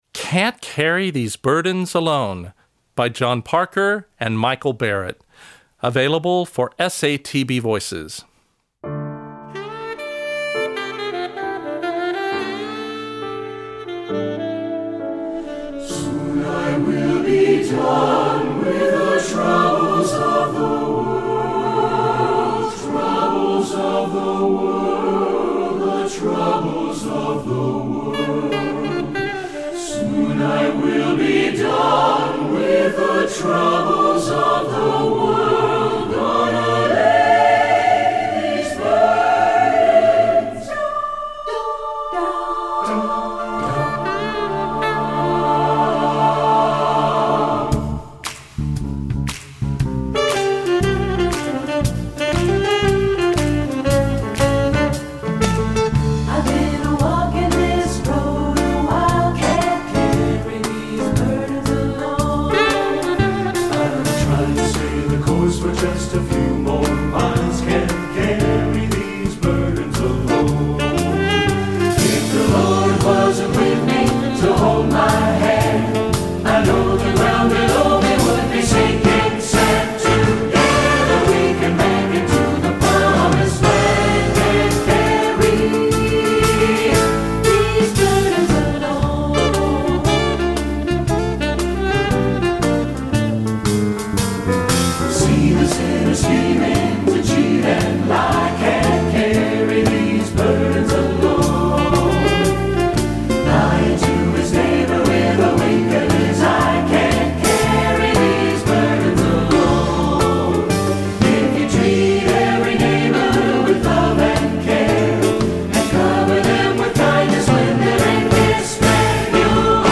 Voicing: SATB and Tenor Sax